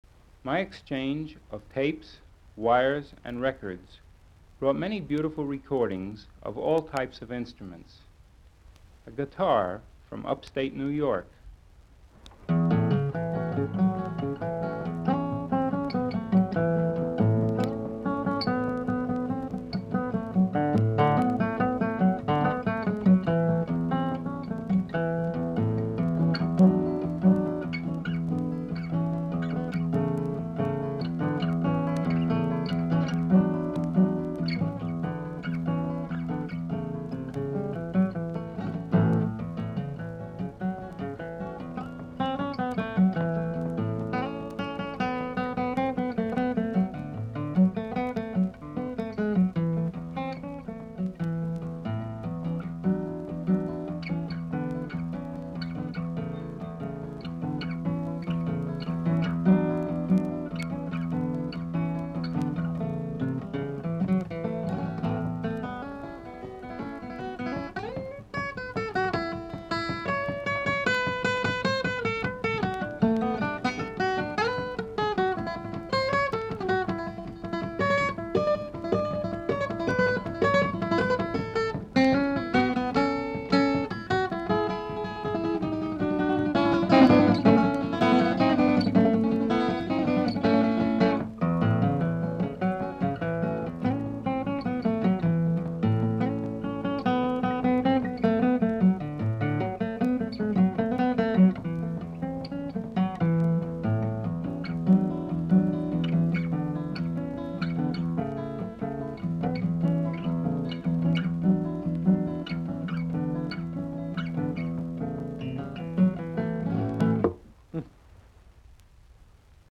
12. Guitar from Upstate, New York